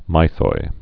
(mīthoi, mĭthoi)